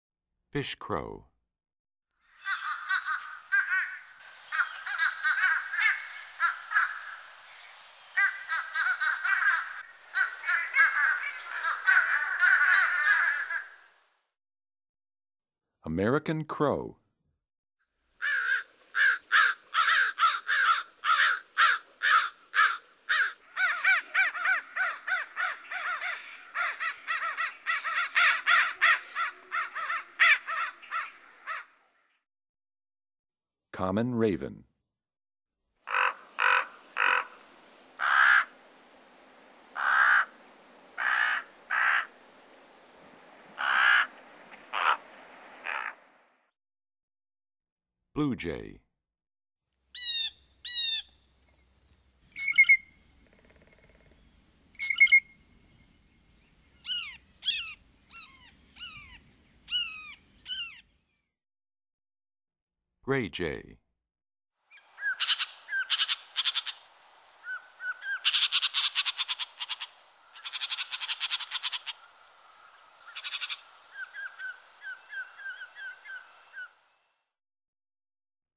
Index of /songs/Animals/Birds/Bird Songs Eastern-Central
21 Fish Crow,American Crow,Common Raven,Blue Jay,Gray Jay.mp3